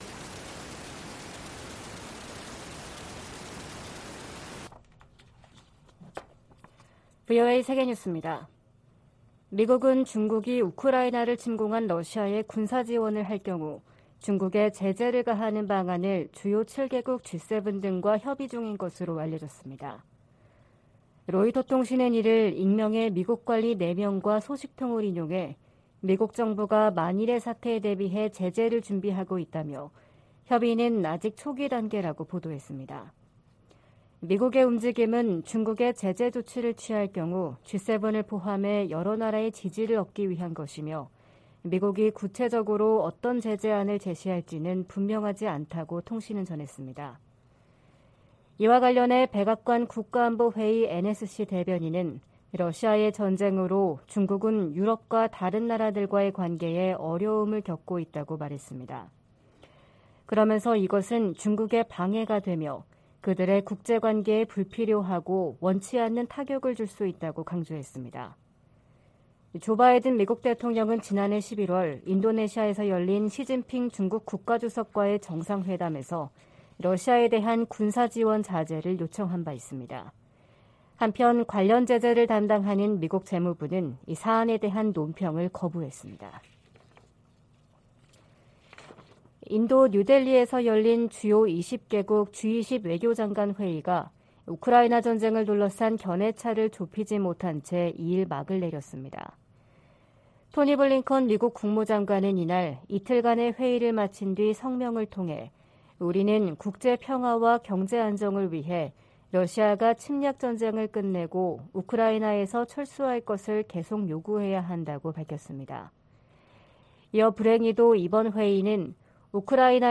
VOA 한국어 '출발 뉴스 쇼', 2023년 3월 3일 방송입니다. 미 국무부는 윤석열 한국 대통령의 3∙1절 기념사가 한일관계의 미래지향적 비전을 제시했다며 환영의 뜻을 밝혔습니다. 최근 실시한 미한일 탄도미사일 방어훈련이 3국 협력을 증진했다고 일본 방위성이 밝혔습니다. 미 하원에 한국전쟁 종전 선언과 평화협정 체결, 미북 연락사무소 설치 등을 촉구하는 법안이 재발의됐습니다.